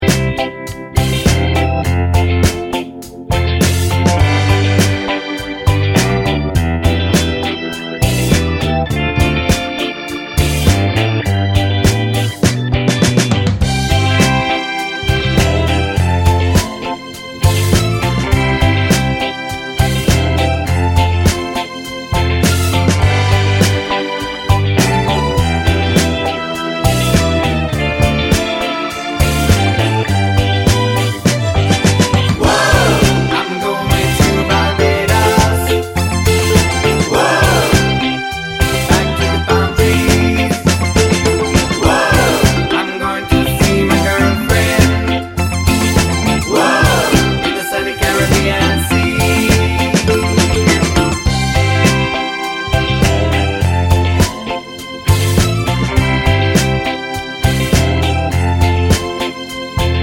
Minus Voiceovers Pop (1970s) 3:41 Buy £1.50